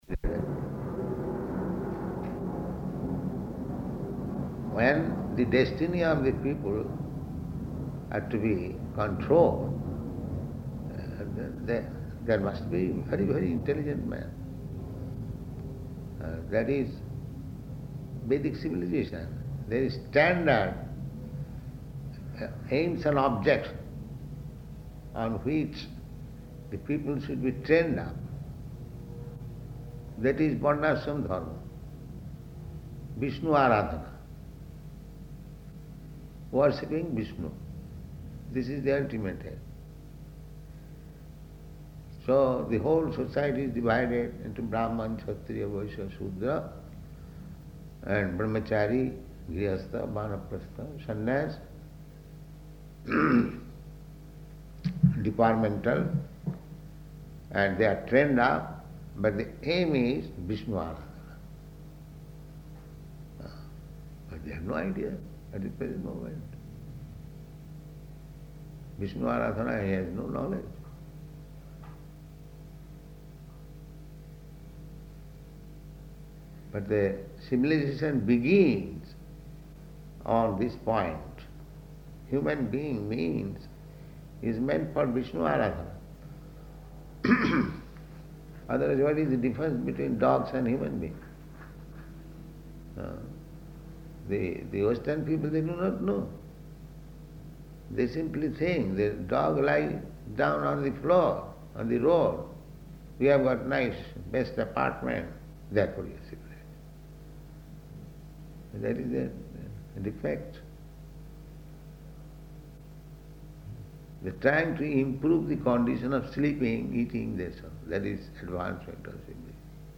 Room Conversation
Type: Conversation